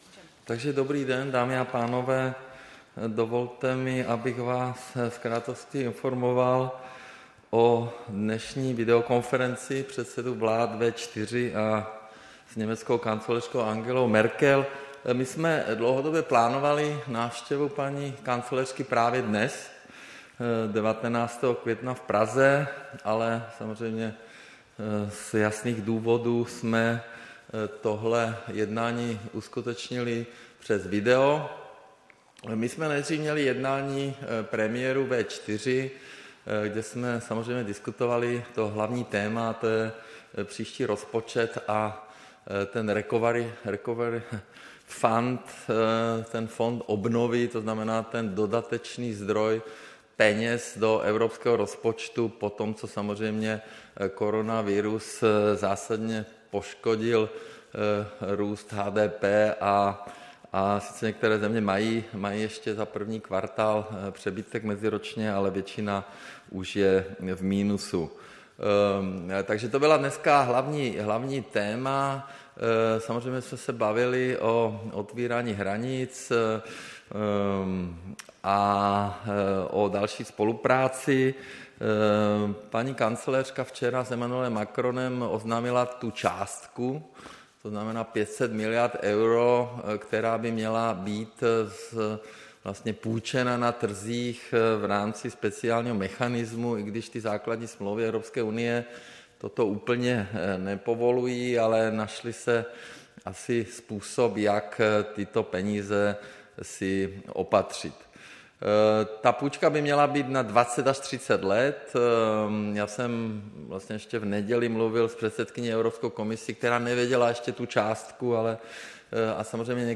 Tisková konference po videokonferenci předsedů vlád V4 s německou kancléřkou Angelou Merkelovou, 19. května 2020
Andrej Babiš, předseda vlády: Takže dobrý den, dámy a pánové.